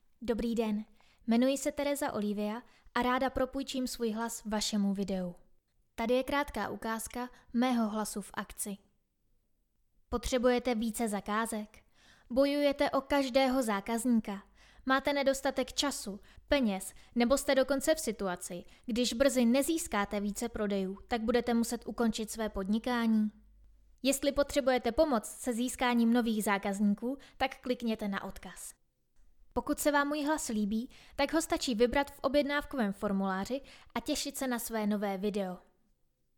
Namluvení voiceoveru/hlasového komentáře dle zadaného textu k vašem videím.
Profesionální ženský hlas - mezzosoprán.